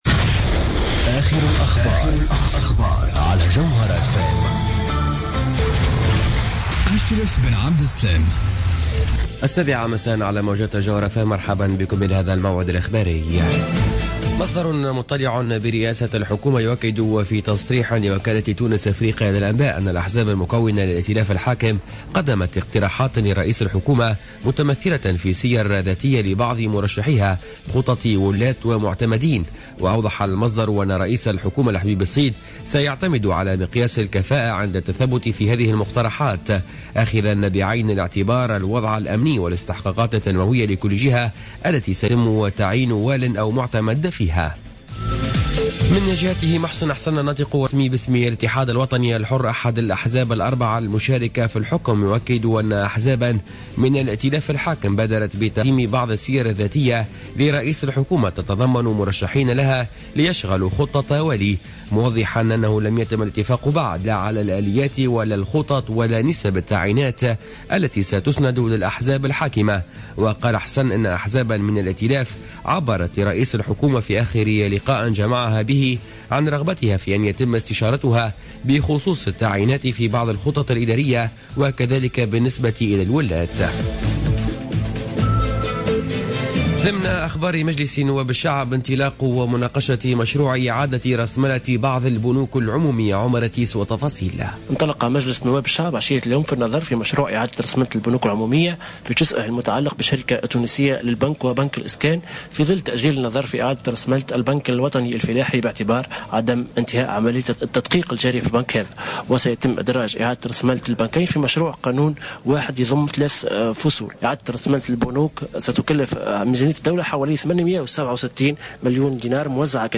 نشرة أخبار السابعة مساء ليوم الخميس 06 أوت 2015